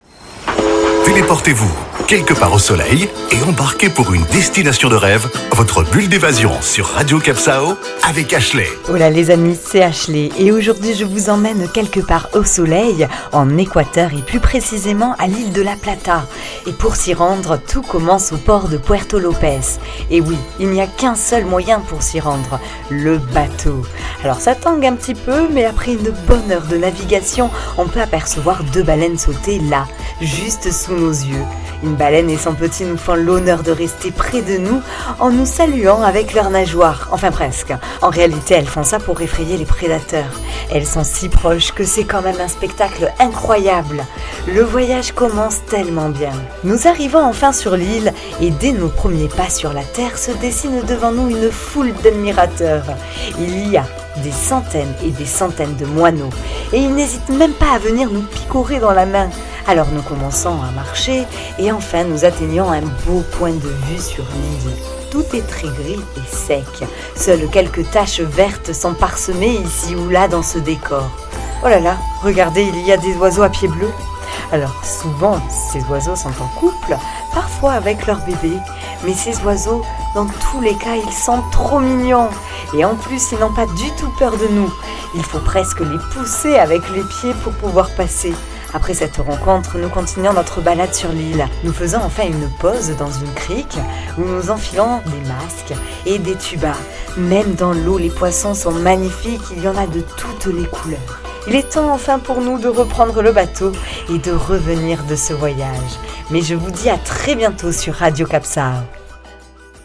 Carte postale sonore : embarquez vers une petite île du parc national de Machalilla, le tout, escortés d'animaux accueillants.